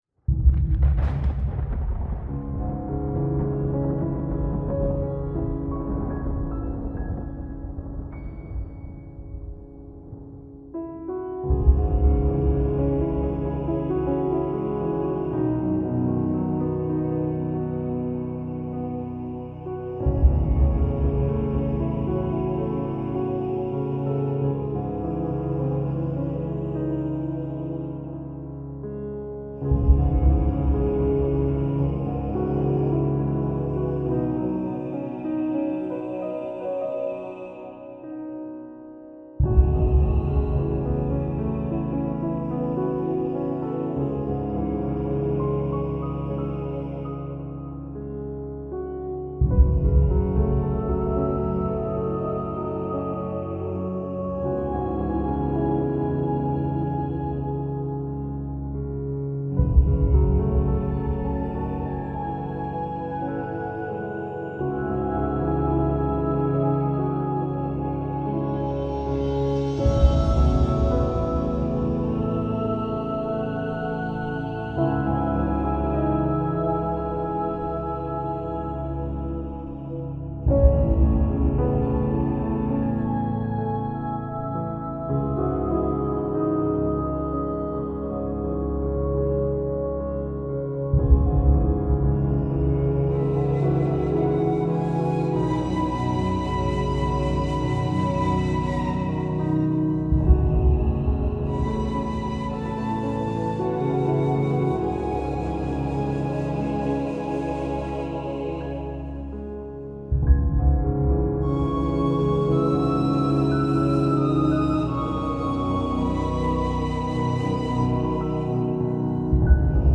All advertisements are thoughtfully placed only at the beginning of each episode, ensuring you enjoy the complete ambient sounds journey without any interruptions. This commitment to your uninterrupted experience means no sudden advertising cuts will disturb your meditation, sleep, or relaxation sessions.